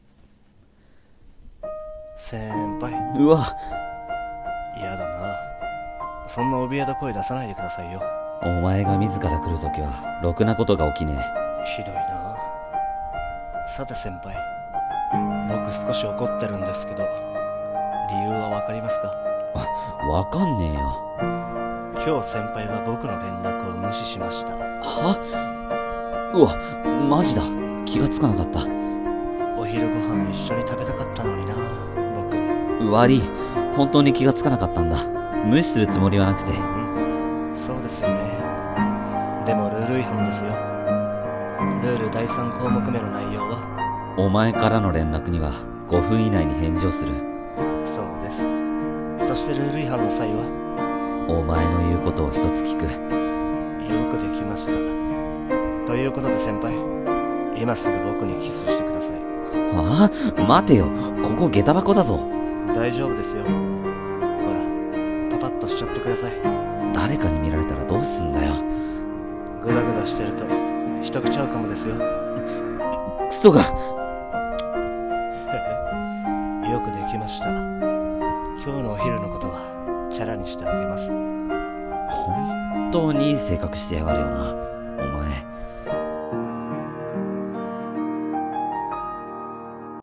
【BL？声劇台本】約束でしょ？【掛け合い】